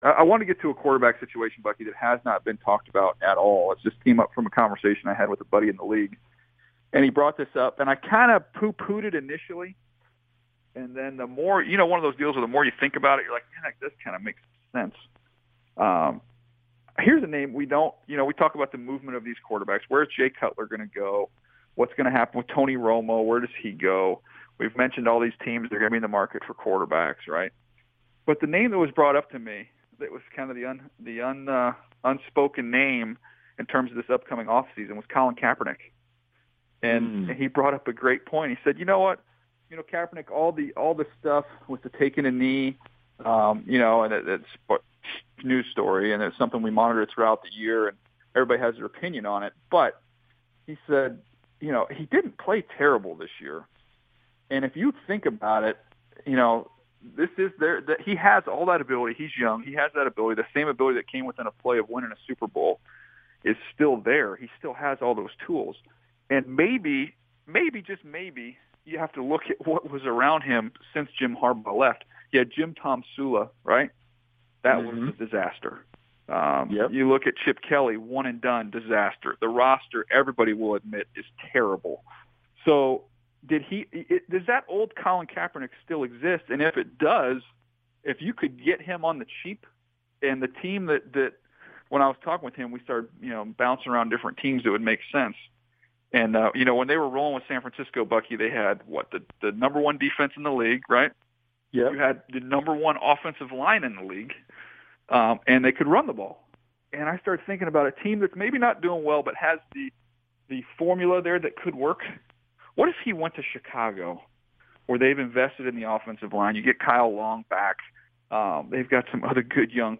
Daniel Jeremiah and Bucky Brooks, two prominent experts and insiders for NFL Network, discussed Kaepernick on the “Move The Sticks” podcast back on January 5th.